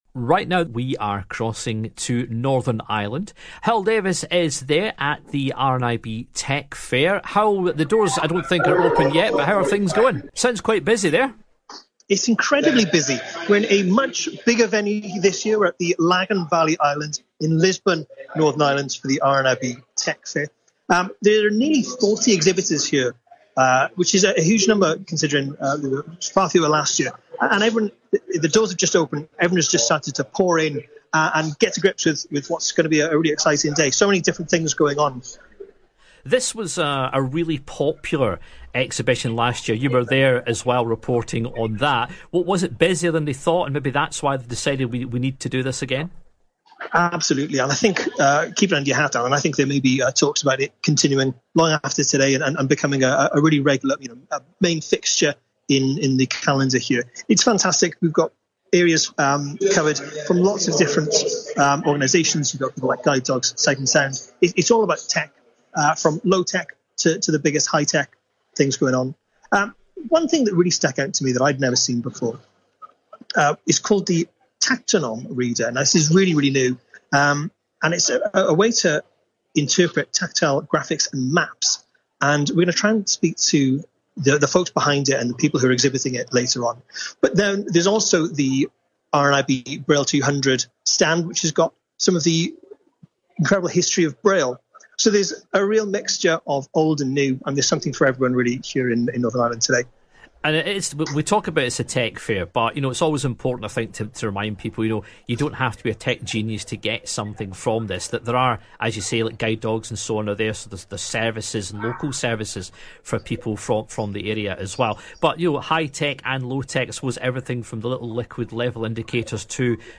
RNIB Tech for Life Fair - Daily Connect Interview